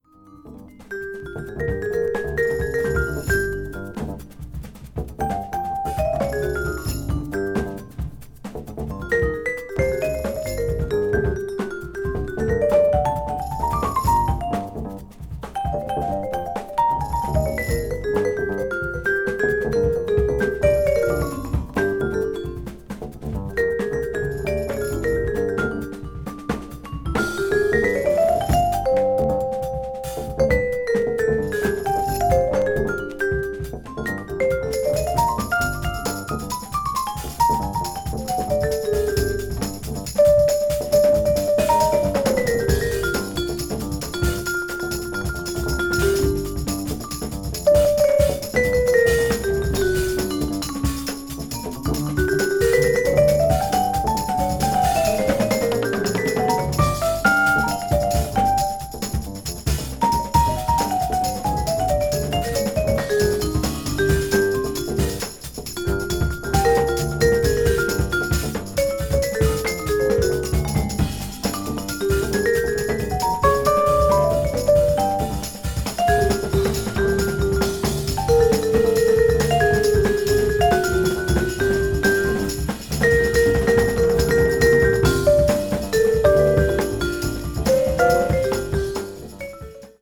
contemporary jazz   ethnic jazz   free jazz